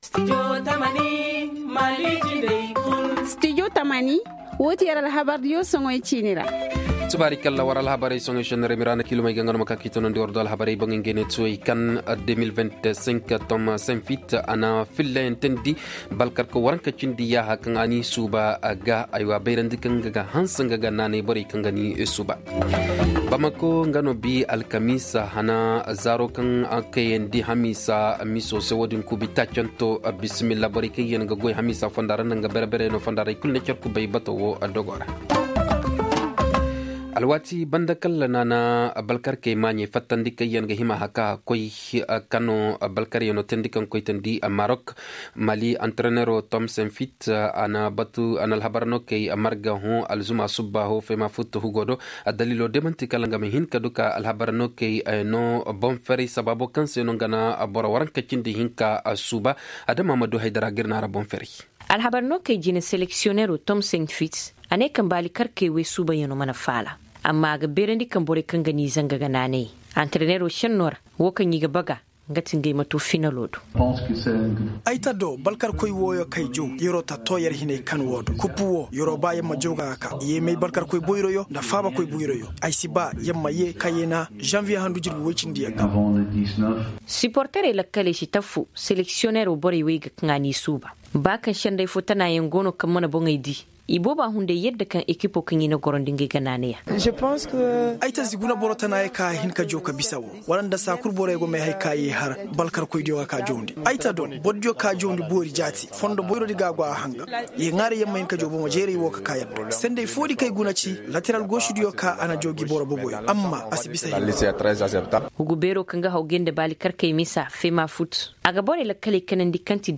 Le journal en Sonhrai du 12 décembre 2025